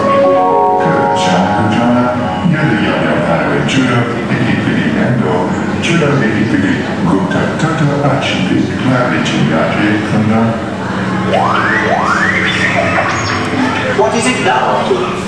Star Tours Alien Boarding Call.